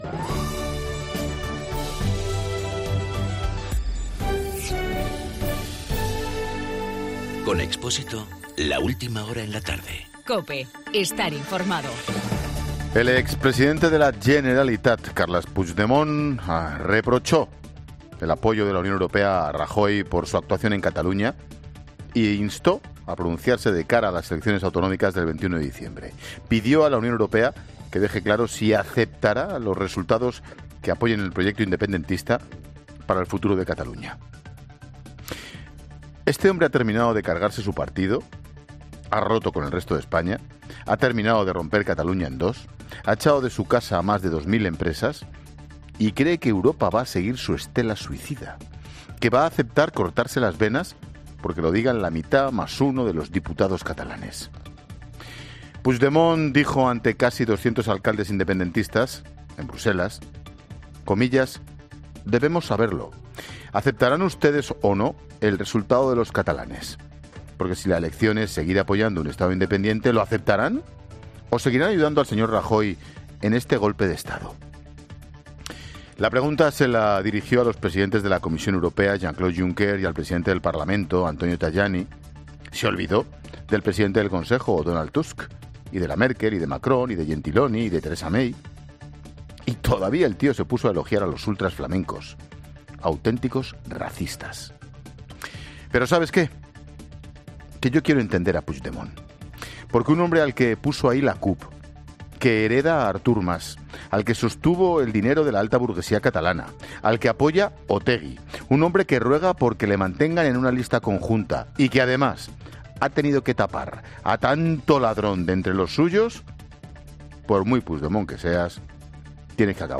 Monólogo de Expósito
El comentario de Ángel Expósito ante el mitin de Puigdemont en Bruselas con el apoyo de los alcaldes catalanes.